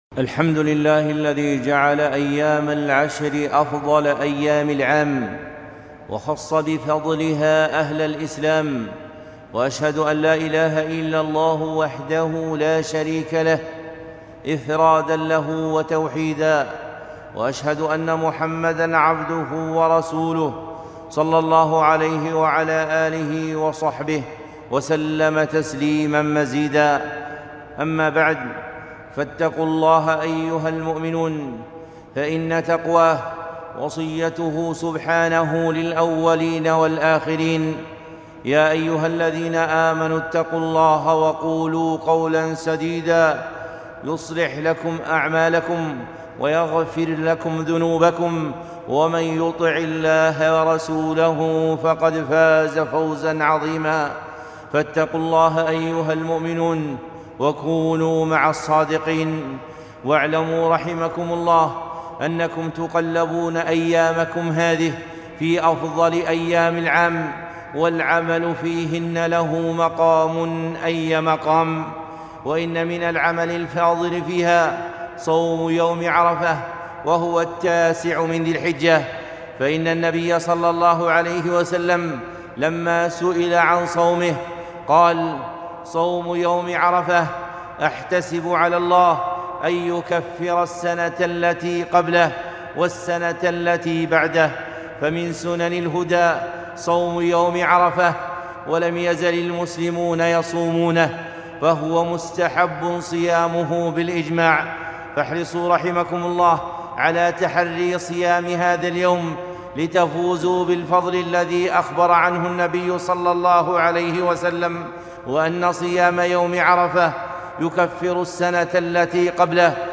خطبة - عملان في عشر ذي الحجة ٣ ذو الحجة ١٤٤١